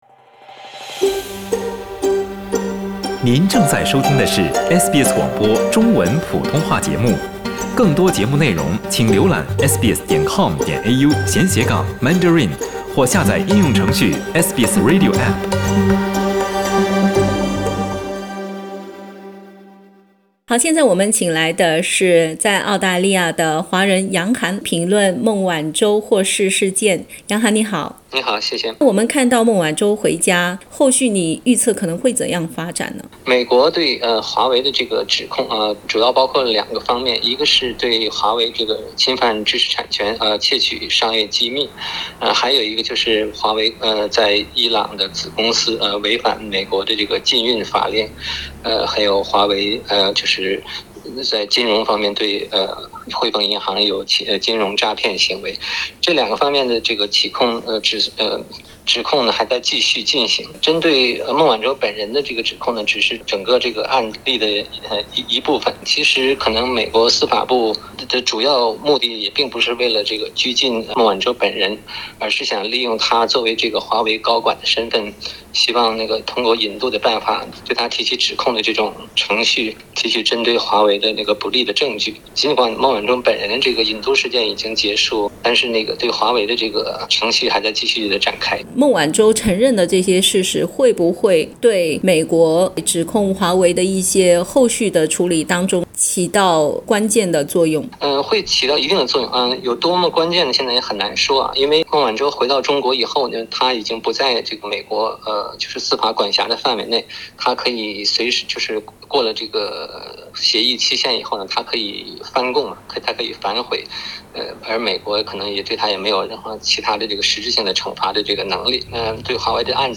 點擊上圖收聽詳細寀訪。